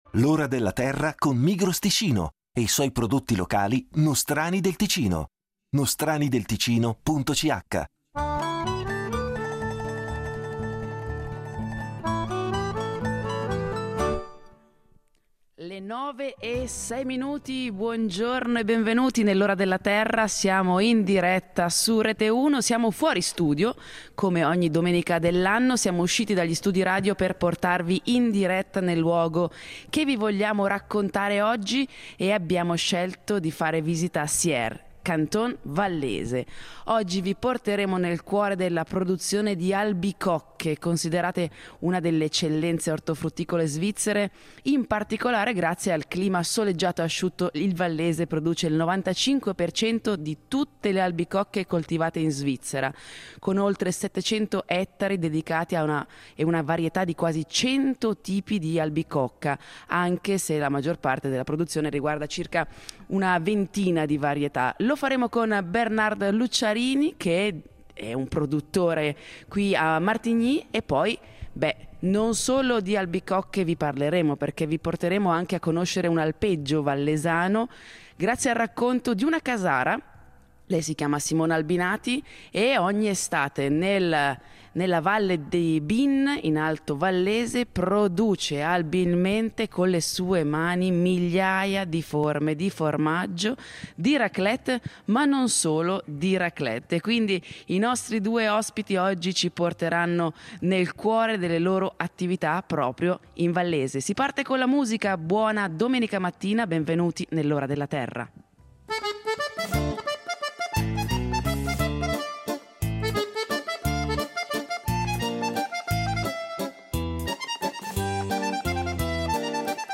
In diretta da Sierre, nel Cantone Vallese, vi porteremo nel cuore della produzione di albicocche.